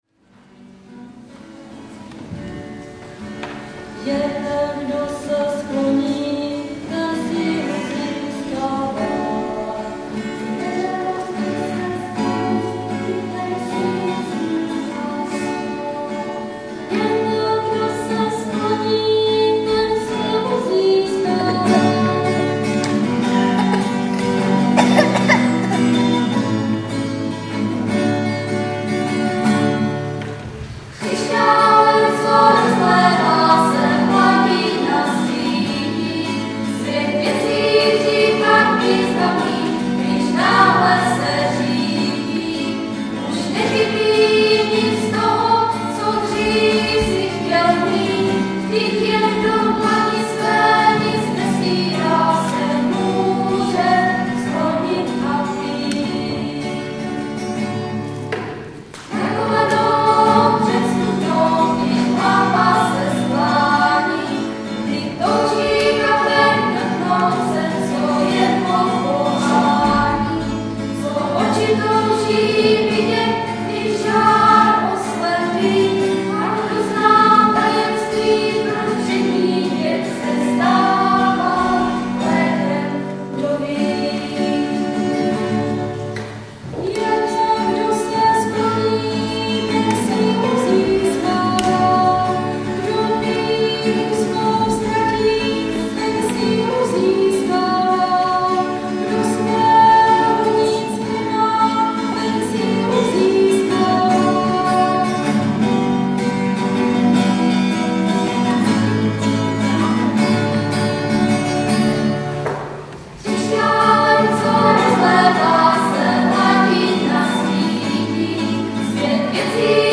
kostel Nivnice … neděla 6.1.2008